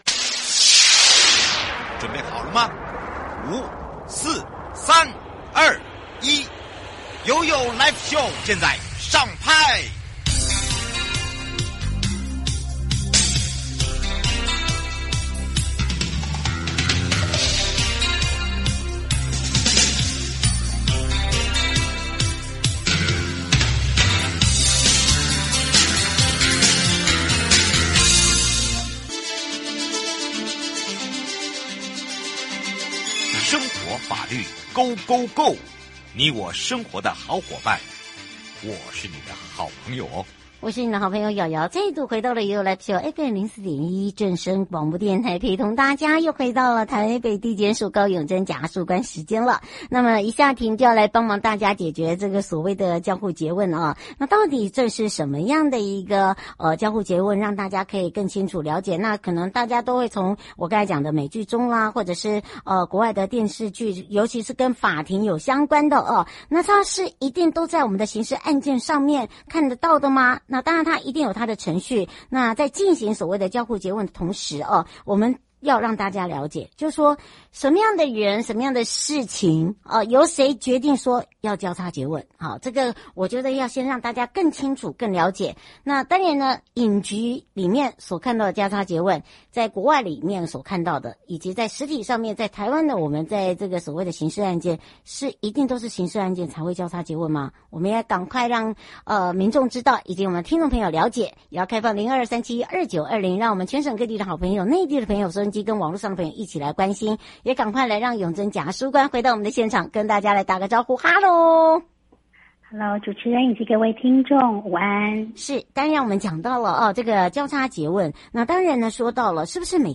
受訪者